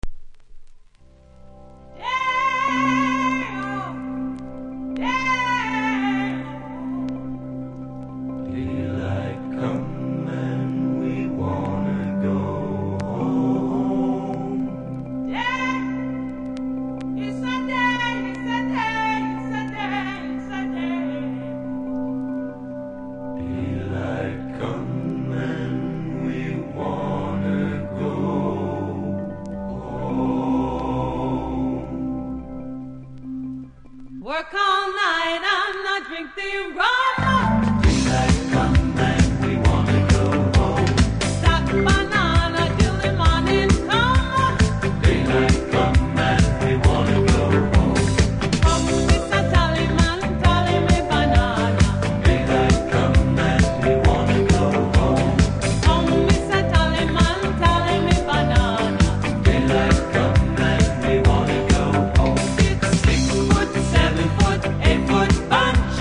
DISCO
出だしのアカペラ部分にノイズ感じますので試聴で確認下さい。